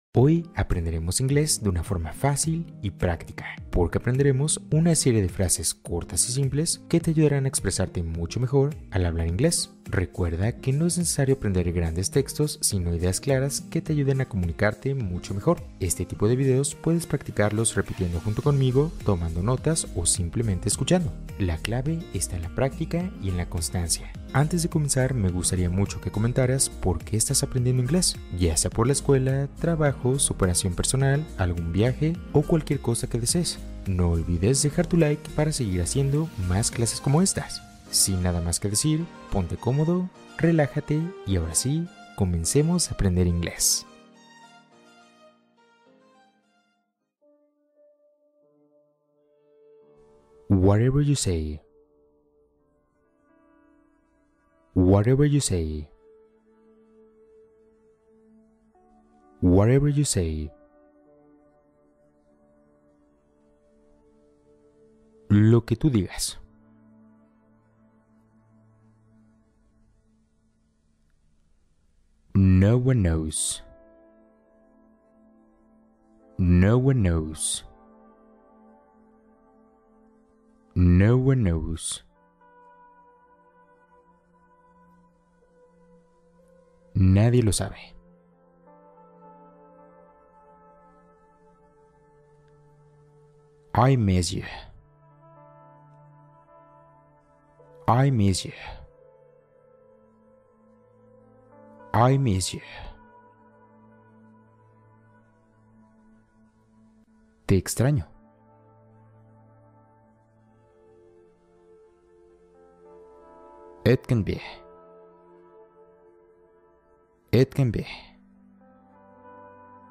Practica escuchando inglés hablado de forma natural | Aprende inglés oyendo ✅